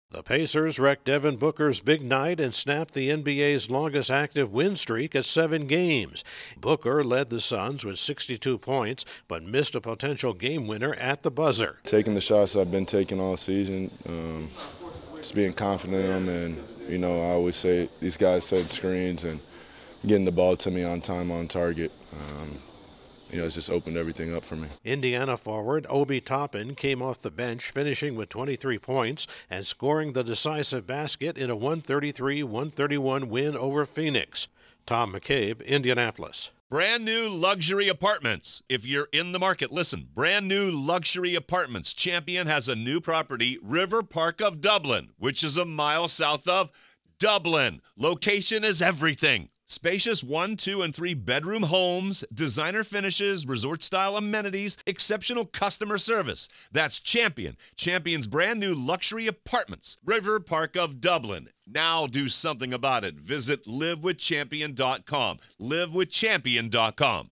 The Suns waste a huge performance by their top shooter. AP correspondent